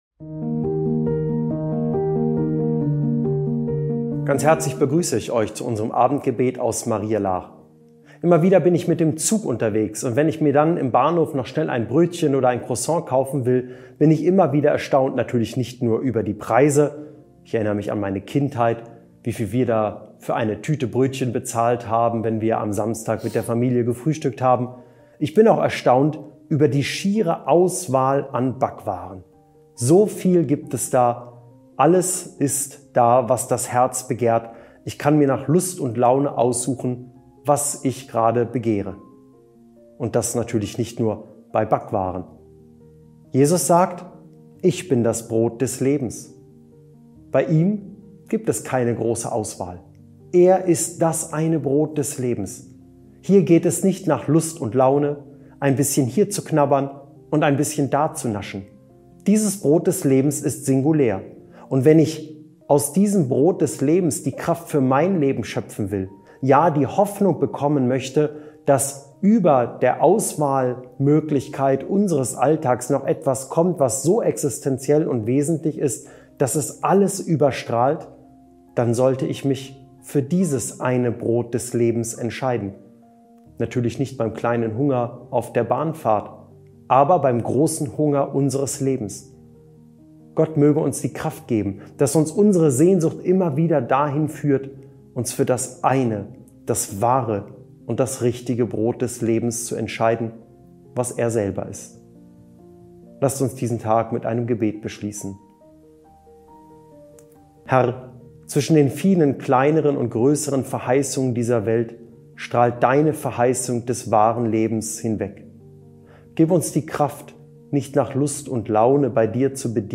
Abendgebet